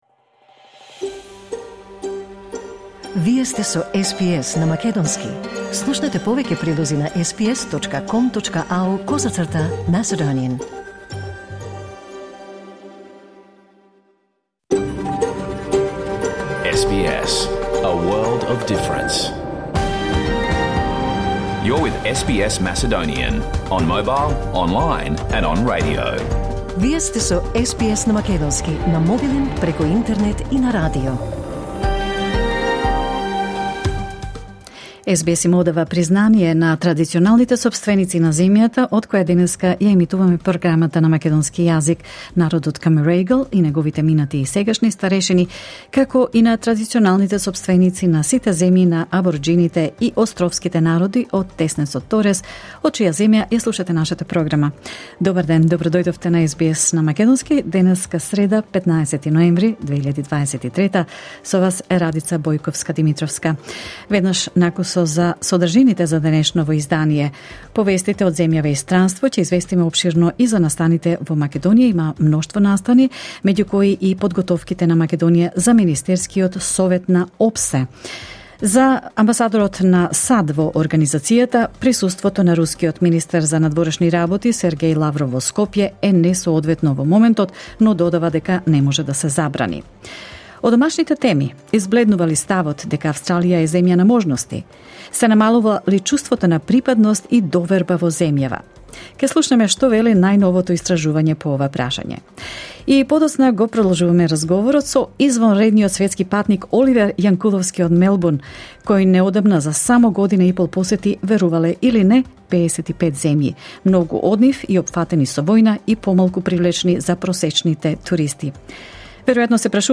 SBS Macedonian Program Live On Air 15 November 2023